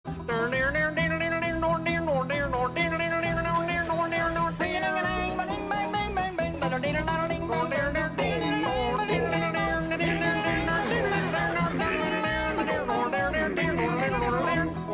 hillbilly-band.mp3